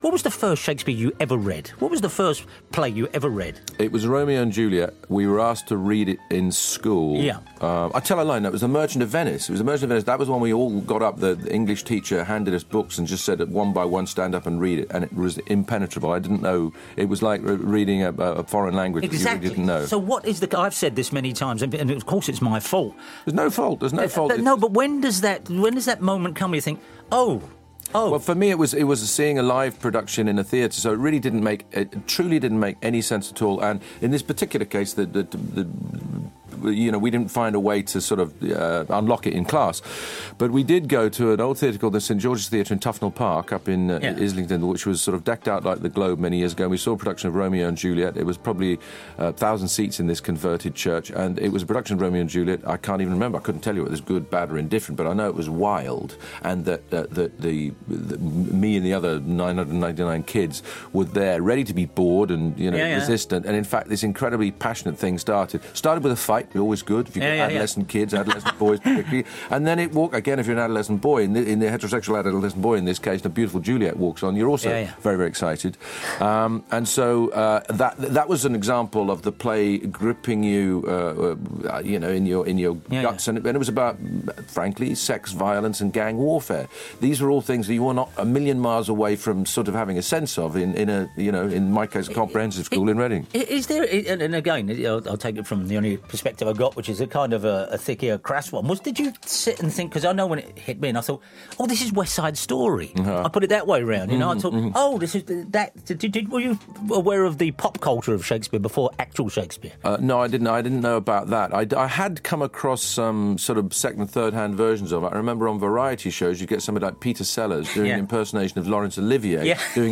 Not sure about Shakespeare? Listen to Sir Kenneth Branagh and you may change your mind.